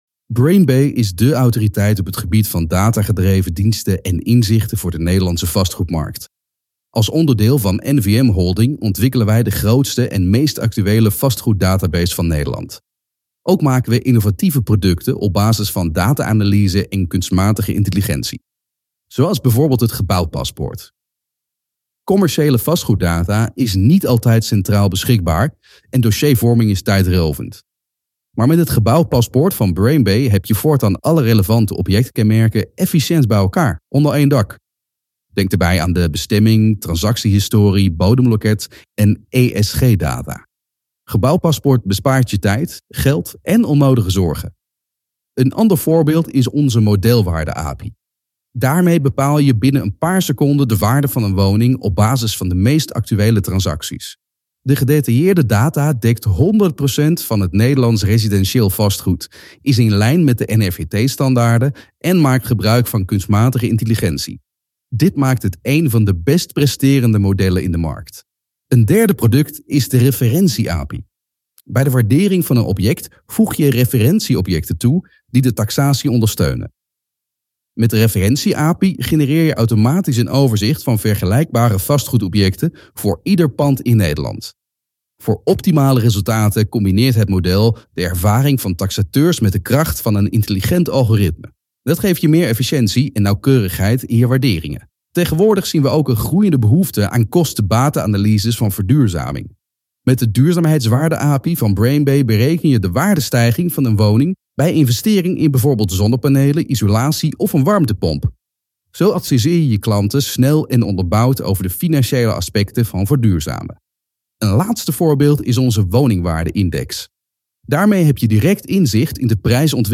Amable, Cálida, Comercial, Profundo, Natural
Corporativo